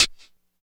85 STE HAT-L.wav